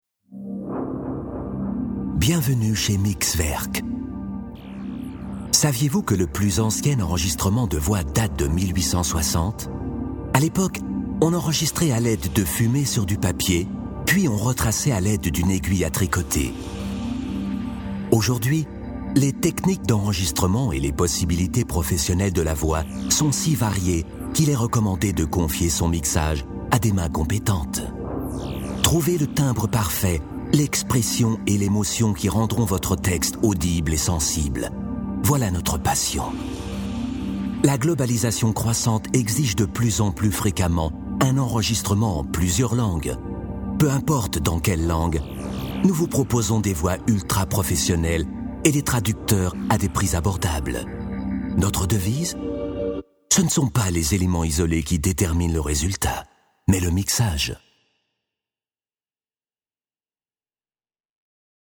Schauspieler, Sprecher, Regisseur etc .....
Sprechprobe: Industrie (Muttersprache):